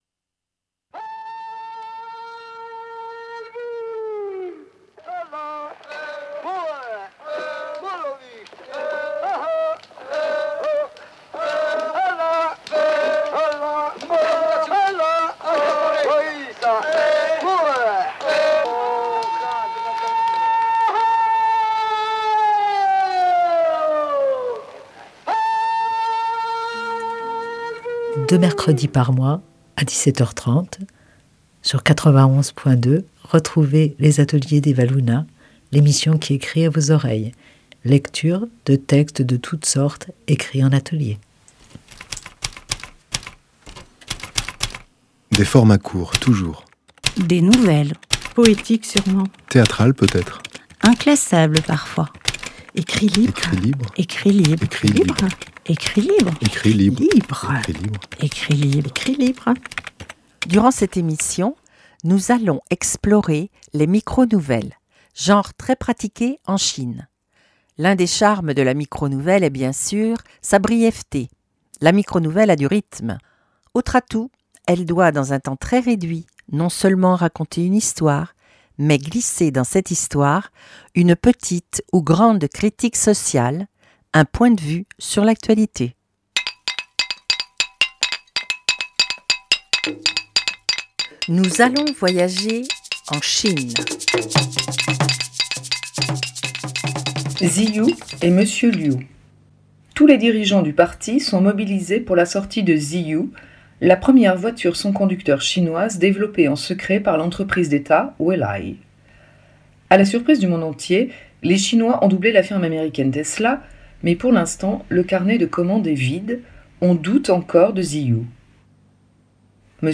textes écrits et lus par l’auteur